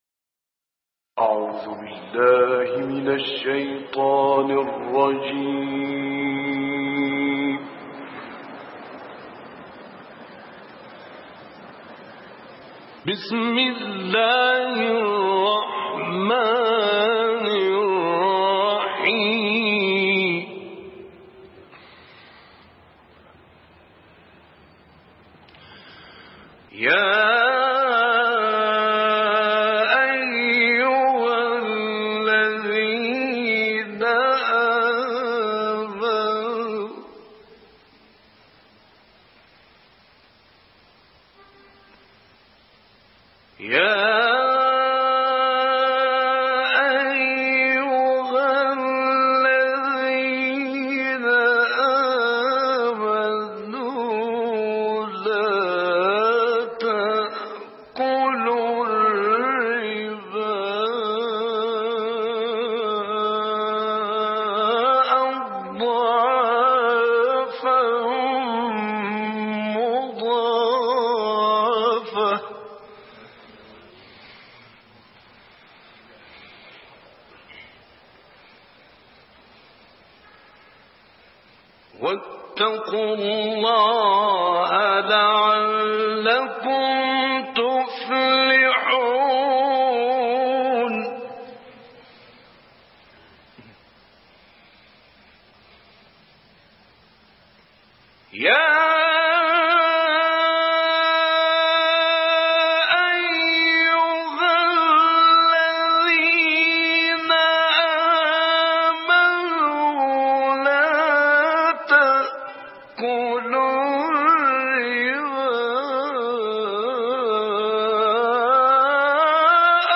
تلاوت مجلسی کوتاه
تلاوت کوتاه مجلسی راغب مصطفی غلوش از آیات 130 تا 132 سوره آل عمران به مدت 4 دقیقه